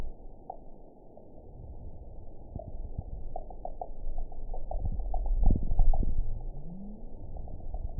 event 921900 date 12/21/24 time 12:18:40 GMT (4 months, 3 weeks ago) score 6.10 location TSS-AB03 detected by nrw target species NRW annotations +NRW Spectrogram: Frequency (kHz) vs. Time (s) audio not available .wav